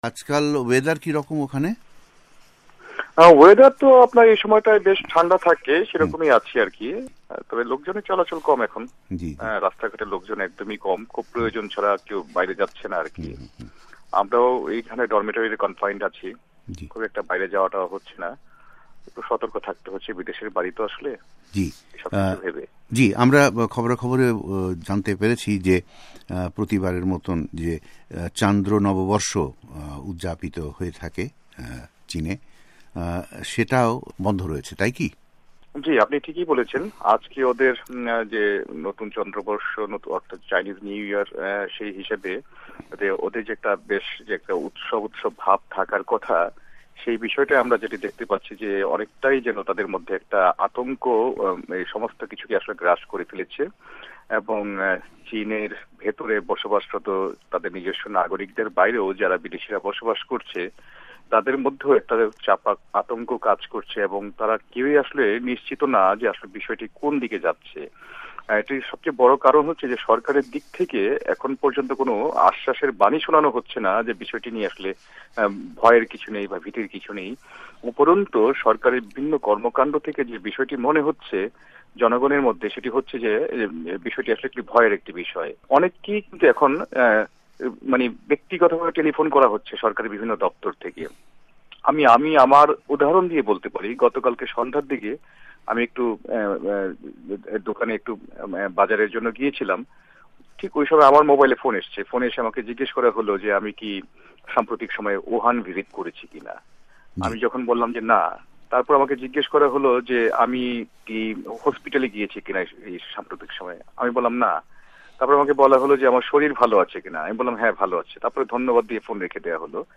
কথোপকথন